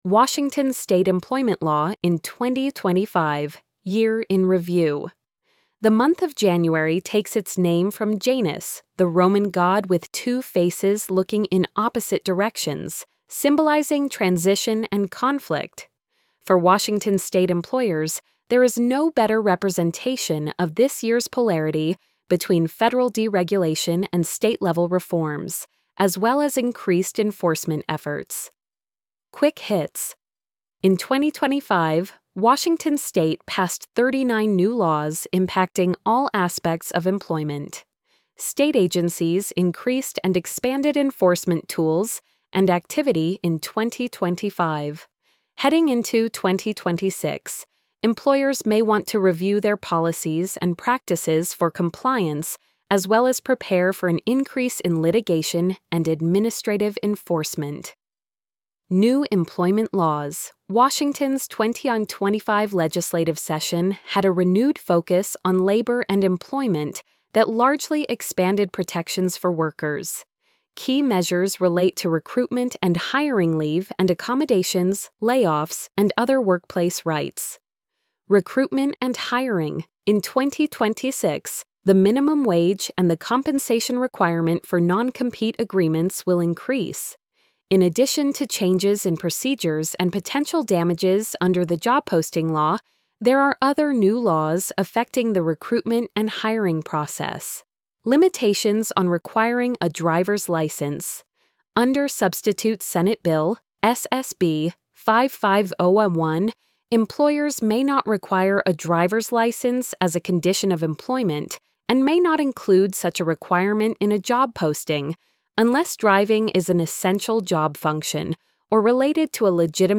washington-state-employment-law-in-2025-year-in-review-tts.mp3